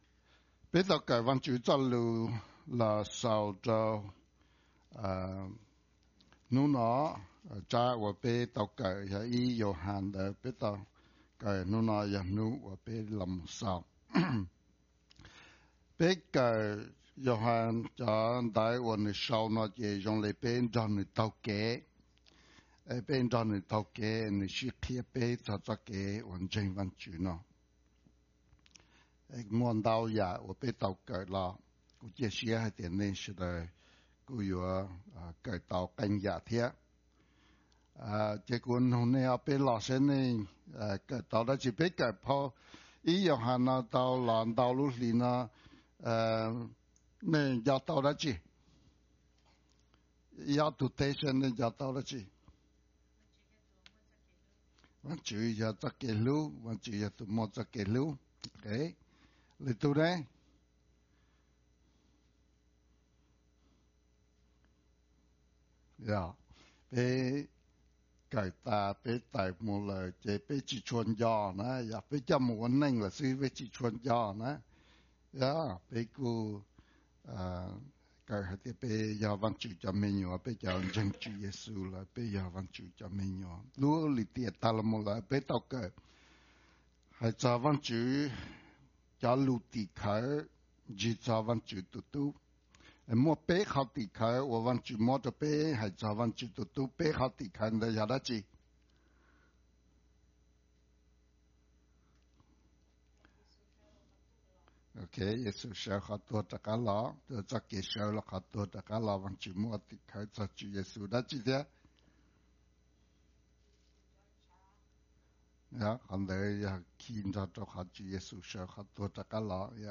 2019-03-03 – English Service